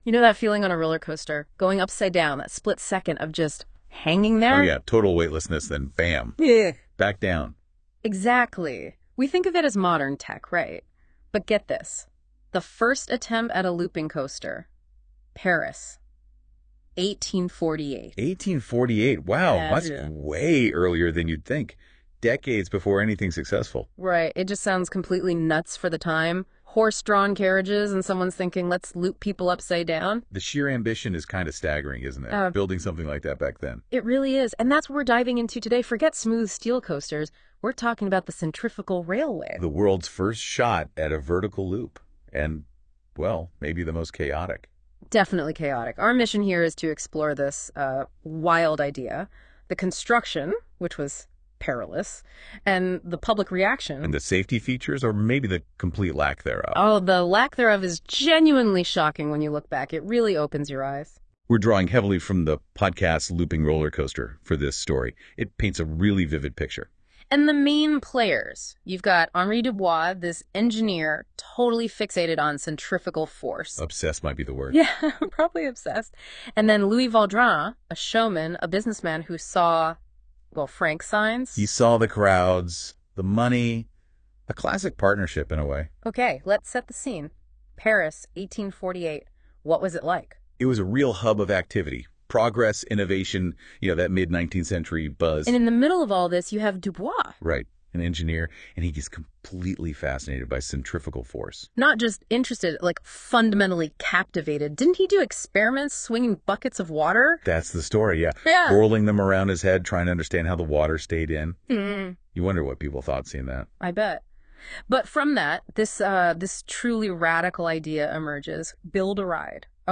Rides From Yesteryear by Robyn Miller via AI This is an experiment. No humans participated in the creation or performance of this podcast.
The AI wrote, produced, and generated the voices in about three minutes.